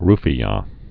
(rfē-yä)